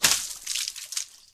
BloodExplosion.wav